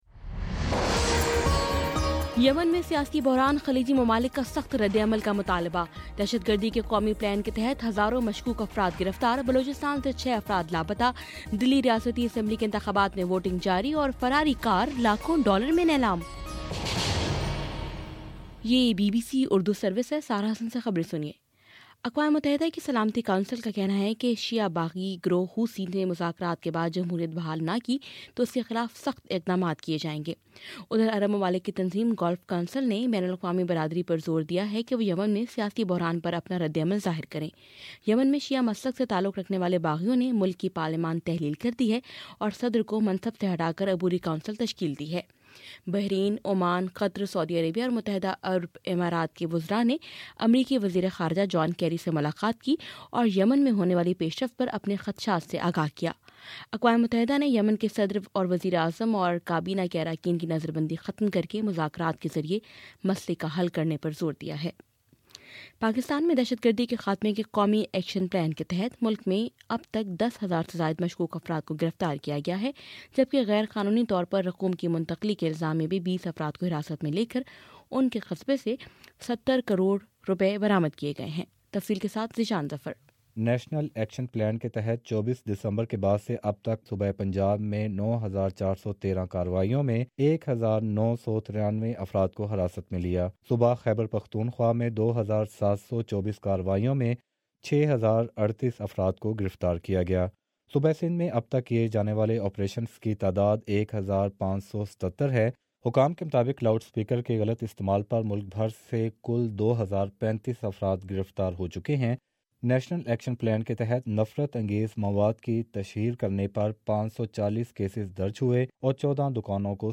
فروری07: صبح نو بجے کا نیوز بُلیٹن